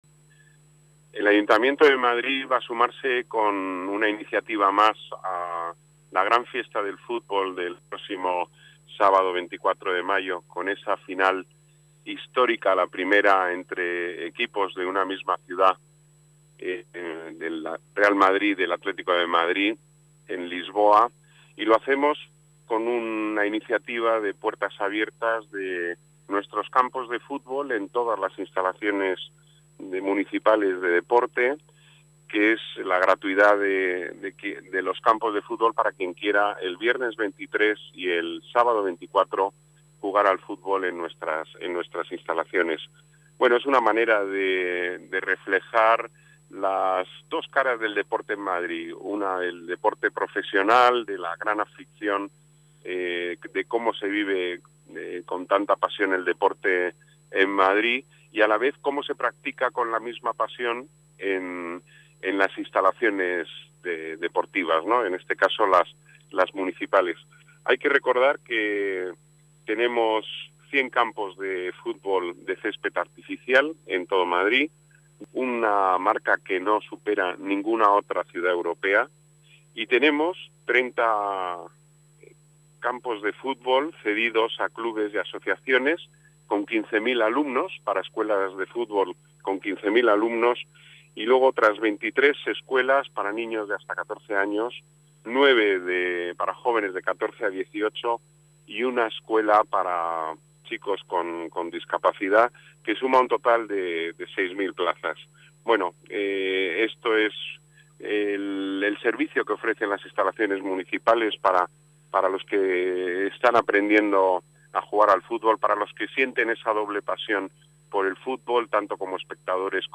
Nueva ventana:Declaraciones del delegado de las Artes, Deportes y Turismo, Pedro Corral